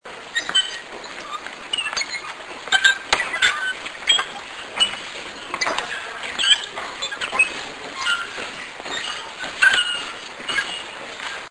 His security arrangement against assassination by ninjas was to fill the place with nightingale flooring, floors that squeak musically as you walk over them.
Ninomaru palace, the main building of Nijo castle was constructed in the early 17th century. The home of the shogun, it contained 'nightingale floors' (inset), floorboards which creak no matter how lightly you tread on them (
nightingale.mp3